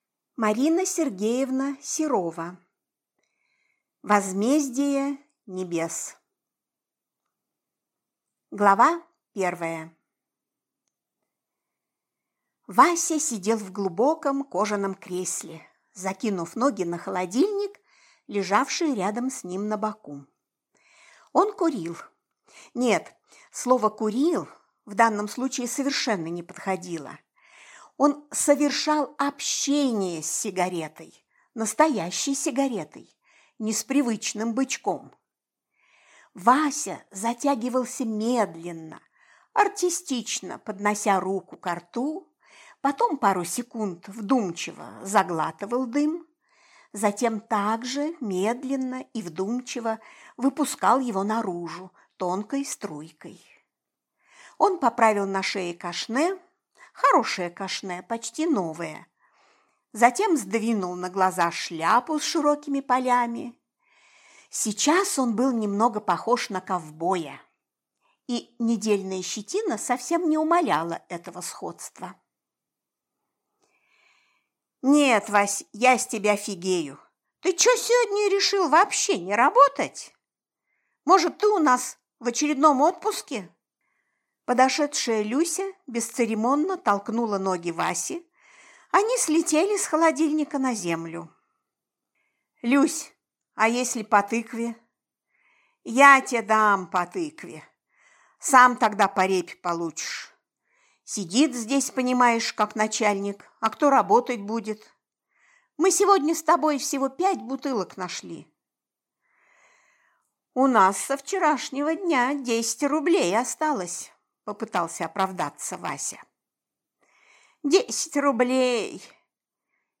Аудиокнига Возмездие небес | Библиотека аудиокниг
Прослушать и бесплатно скачать фрагмент аудиокниги